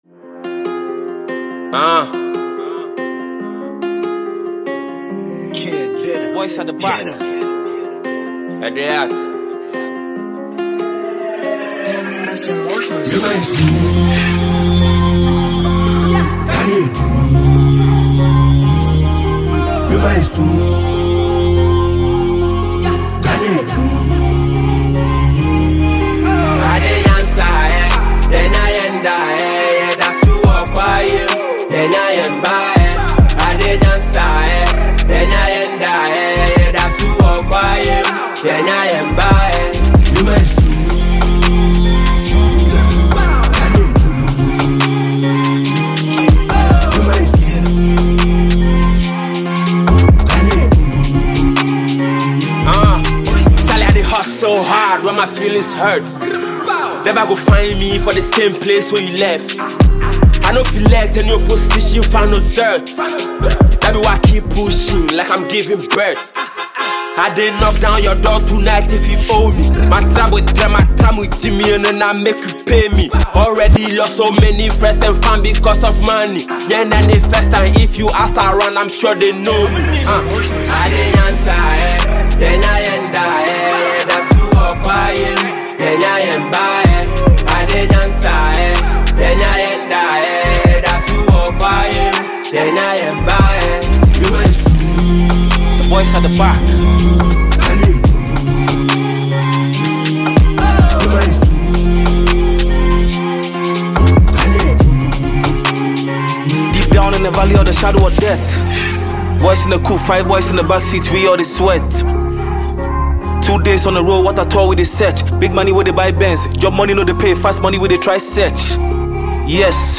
Ghanaian talented singer and songwriter
featuring Ghanaian rapper